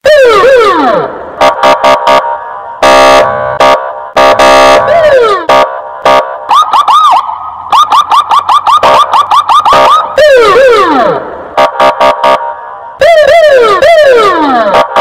Звуки полицейской крякалки
Звук сирены полицейской машины